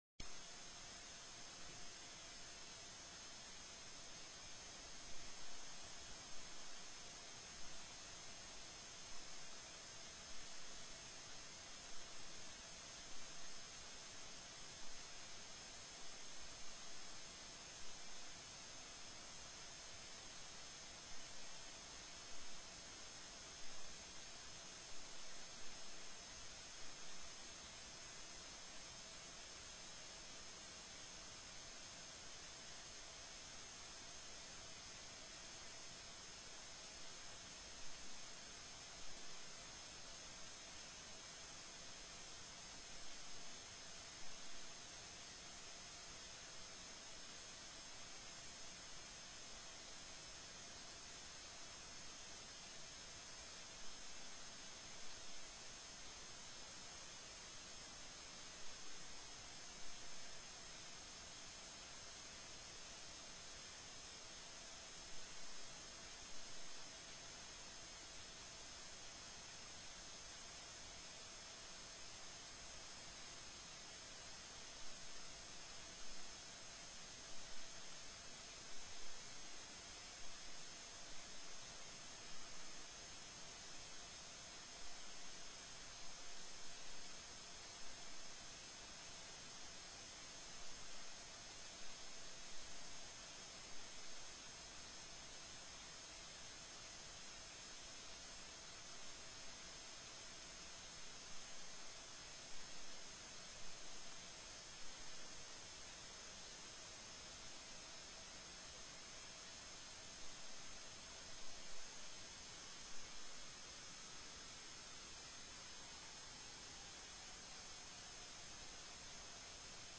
04/08/2016 04:00 PM House CONFERENCE COMMITTEE ON HB256 AND HB257
The audio recordings are captured by our records offices as the official record of the meeting and will have more accurate timestamps.